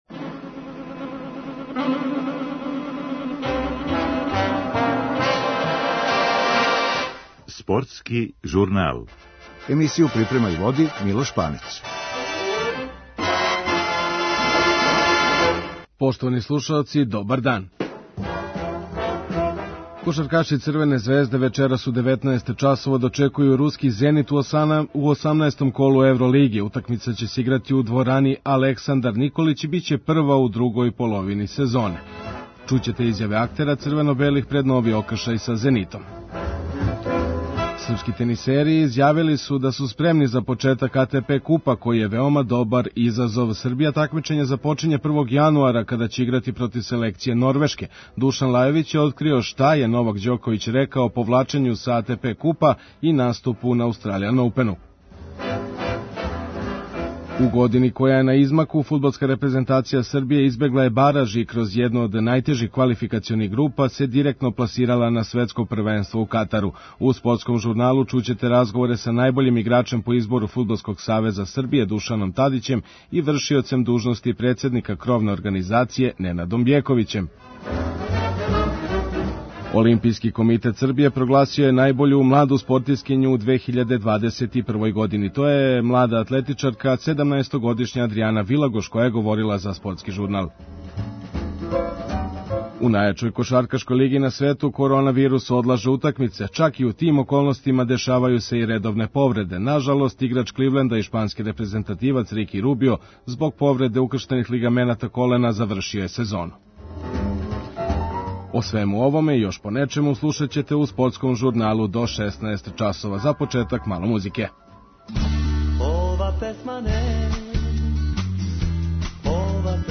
Чућете изјаве актера црвено-белих пред нови окршај са Зенитом.